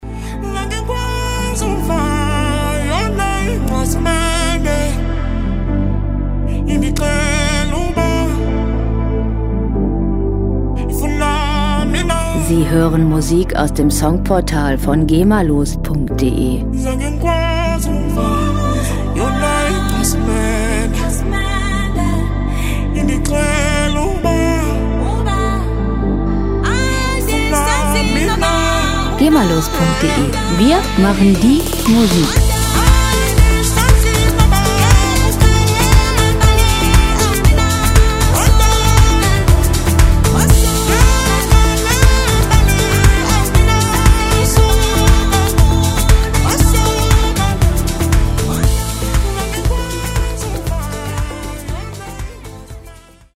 World Pop Music aus der Rubrik "Weltenbummler"
Musikstil: African House
Tempo: 118 bpm
Tonart: A-Moll
Charakter: meoldiös, harmonisch
Instrumentierung: Synthesizer, afrikanische Sänger, Kalimba